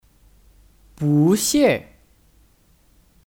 不谢 (Bú xiè 不谢)